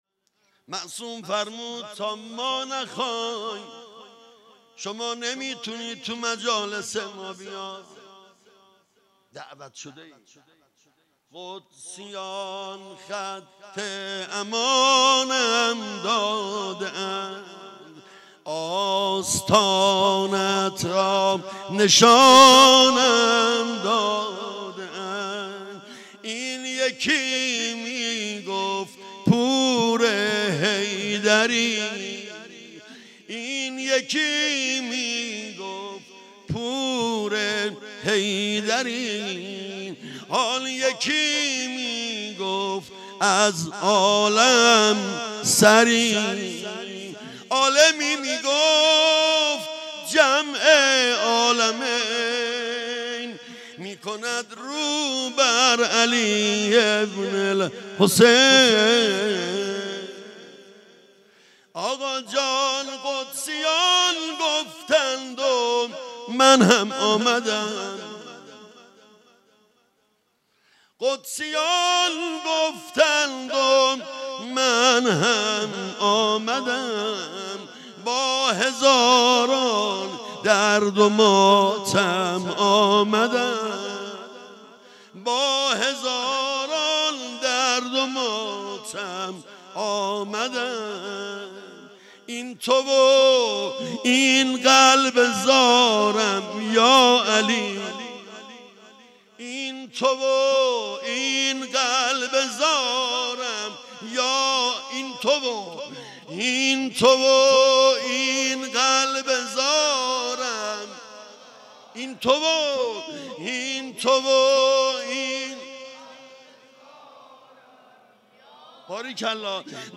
مداحی
در مسجد حضرت امیر(ع) برگزار شد.